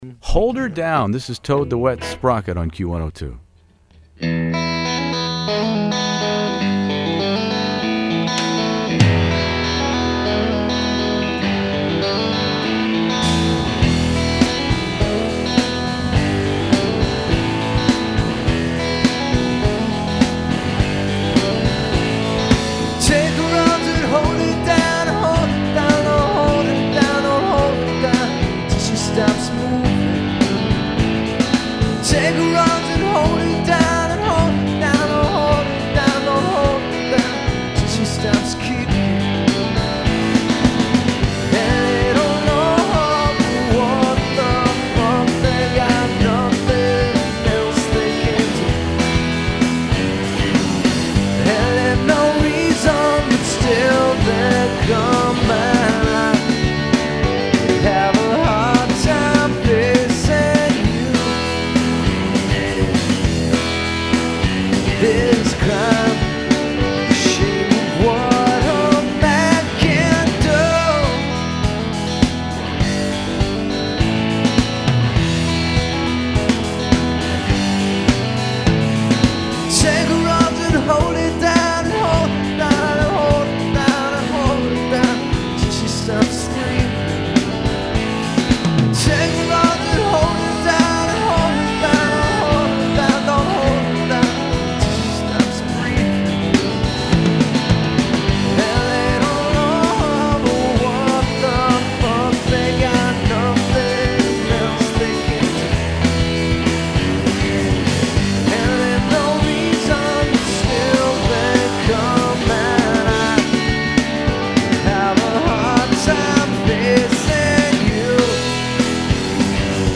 singer/guitarist